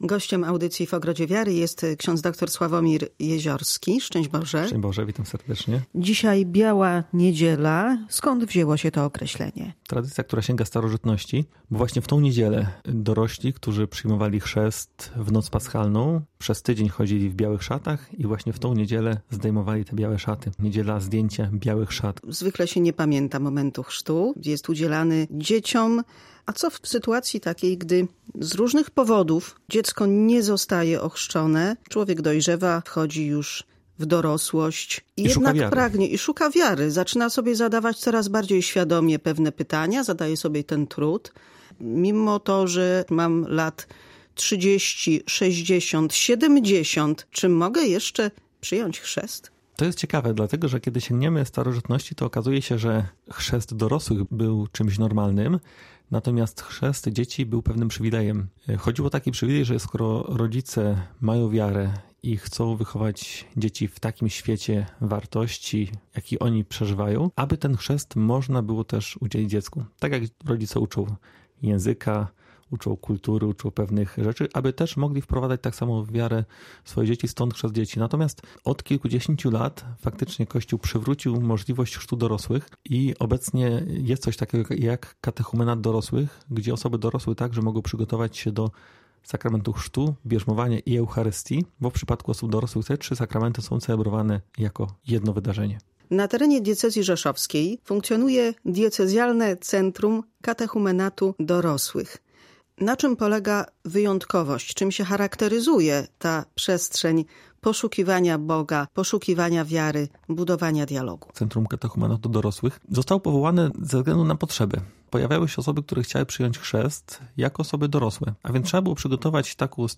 Biała niedziela – nazwa pochodzi od strojów noszonych do tego dnia przez ochrzczonych w Wielką Sobotę. Pięć osób przygotowuje się do przyjęcia sakramentu chrztu, bierzmowania i Eucharystii w Diecezjalnym Centrum Katechumenatu Dorosłych w Rzeszowie. To niepowtarzalne historie i decyzje – mówił gość magazynu katolickiego